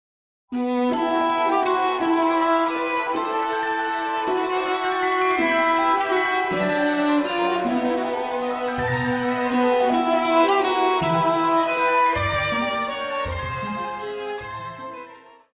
Pop
Violin
Band
Instrumental
World Music
Only backing